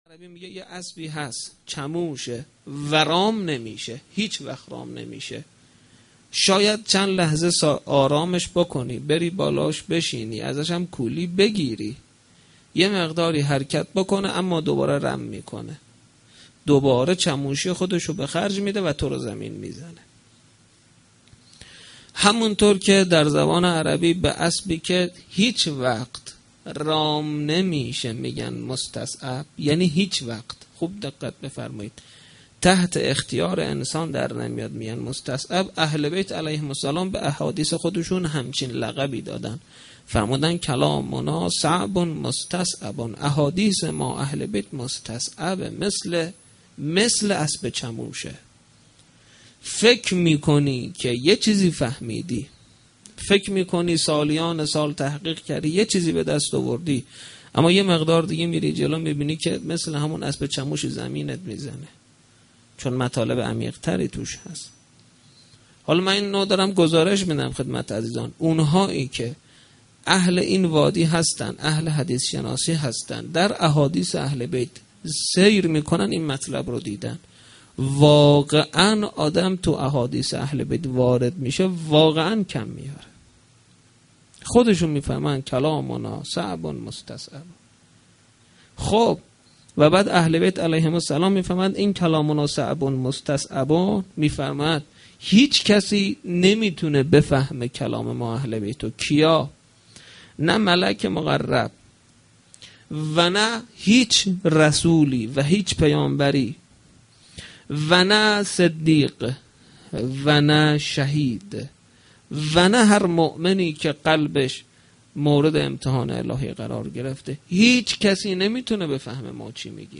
veladate-emam-zaman-a-93-sokhanrani.mp3